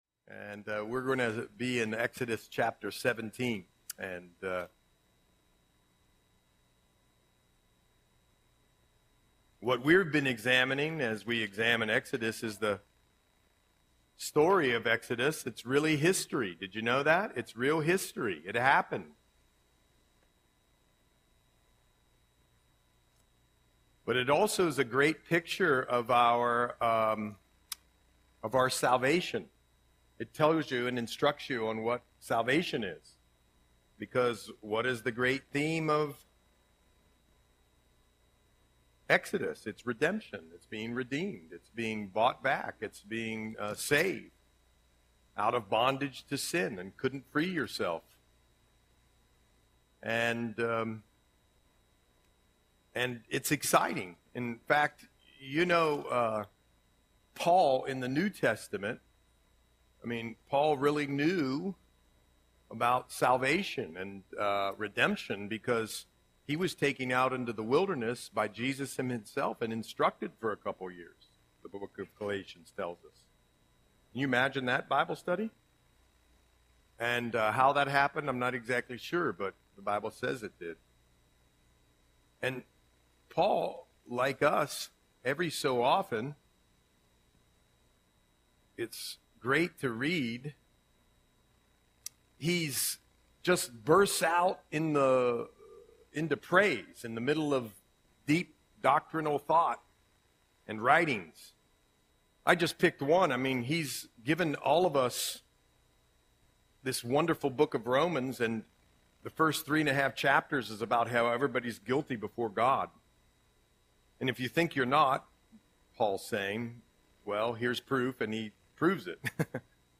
Audio Sermon - February 12, 2025